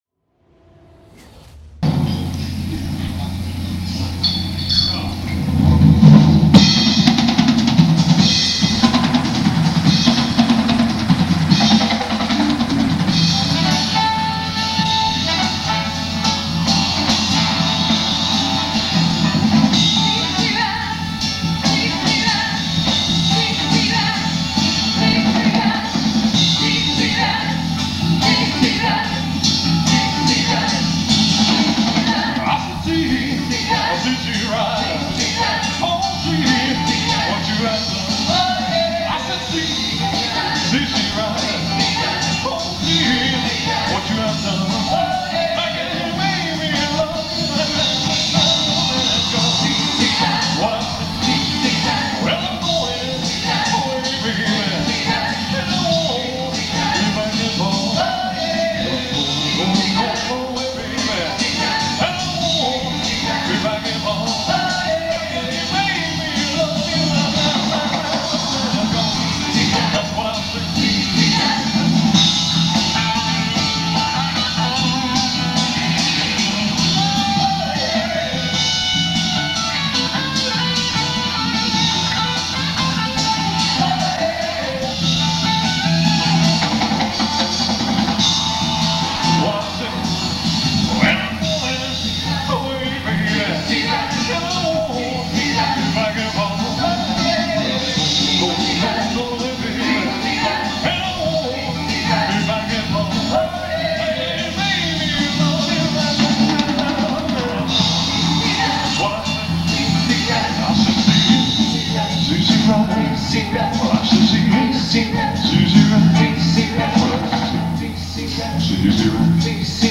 In Concert